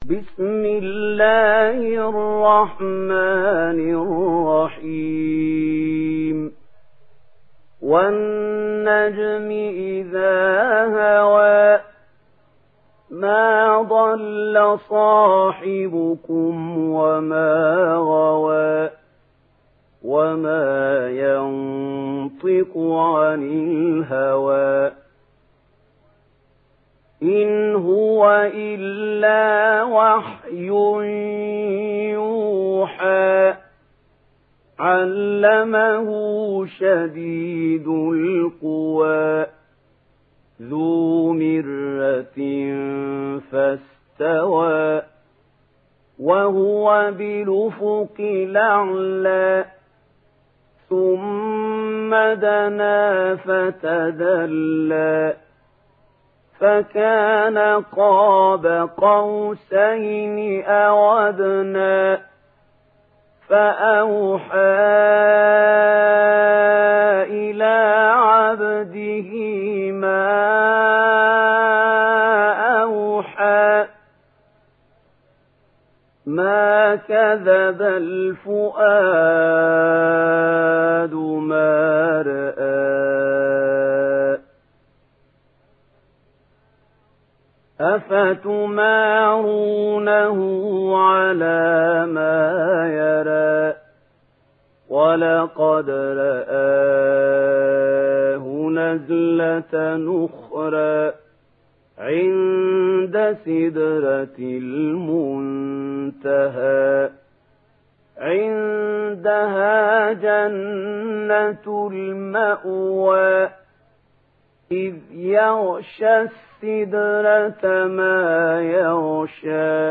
دانلود سوره النجم mp3 محمود خليل الحصري روایت ورش از نافع, قرآن را دانلود کنید و گوش کن mp3 ، لینک مستقیم کامل